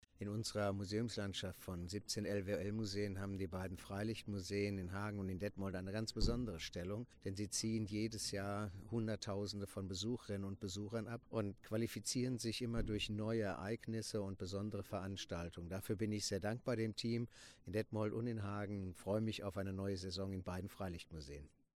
Unterhalb dieser Pressemitteilung finden Sie einen O-Ton vom LWL-Direktor Dr. Wolfgang Kirsch.